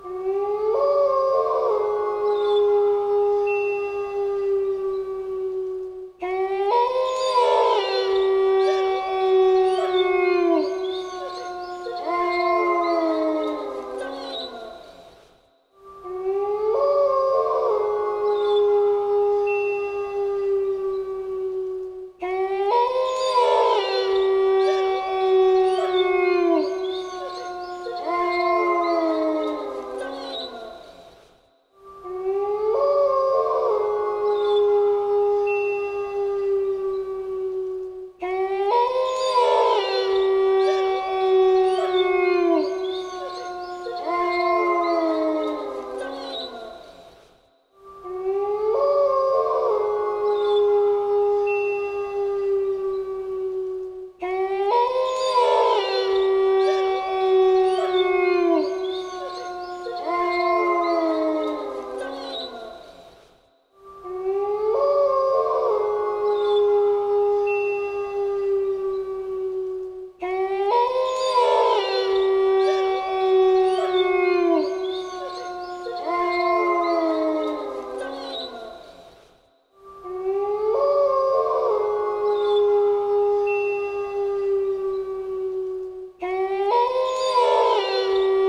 دانلود صدای زوزه گرگ 2 از ساعد نیوز با لینک مستقیم و کیفیت بالا
جلوه های صوتی